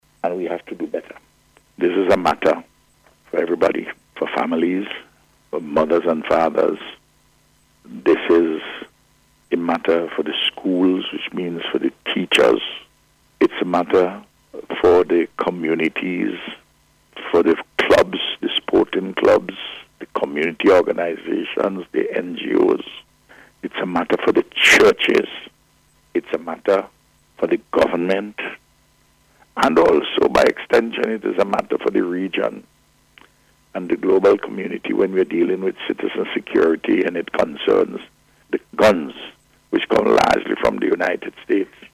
He made this appeal during the Face to Face program aired on NBC Radio today as the nation recorded four homicides over the past weekend.